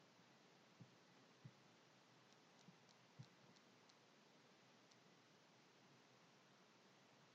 描述：自行车的后轮滴答作响
标签： 自行车 壁虱
声道立体声